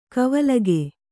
♪ kavalage